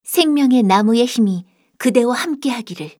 archer_f_voc_lobbystart_b.mp3